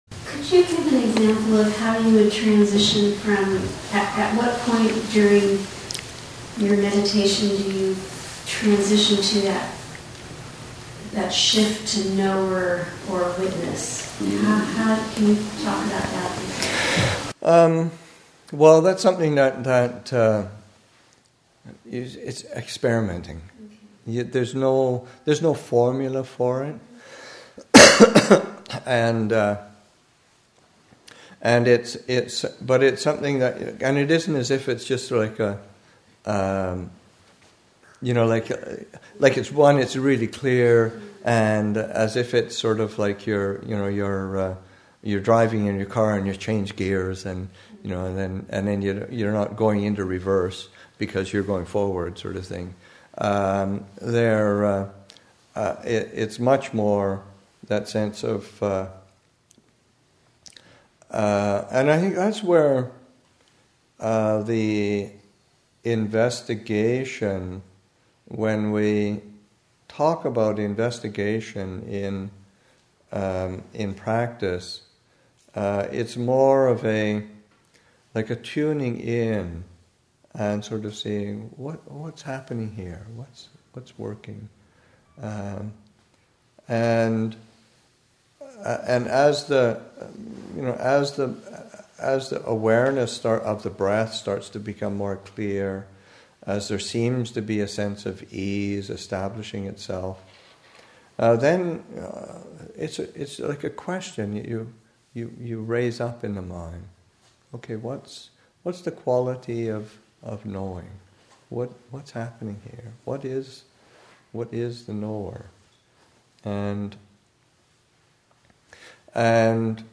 Abhayagiri Buddhist Monastery in Redwood Valley, California